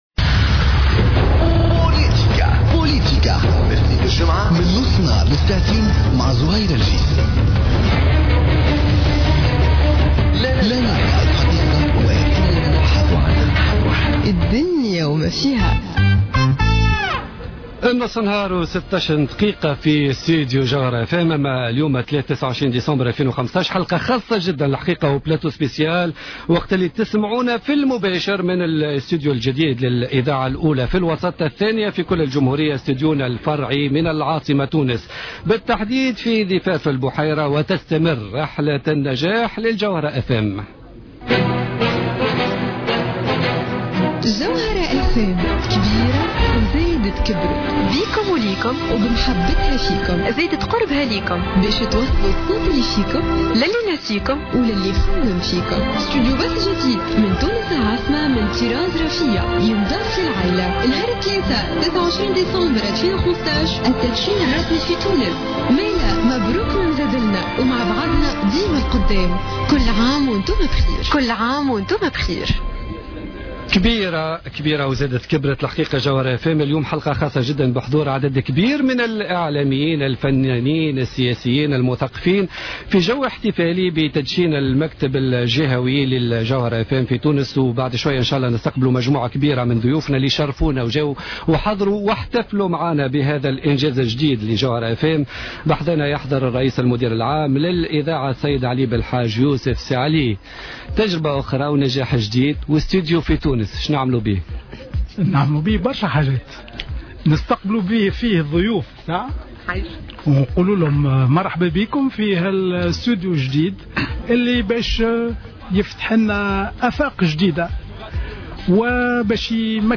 En direct du nouveau studio de Jawhara FM à Tunis